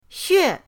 xue4.mp3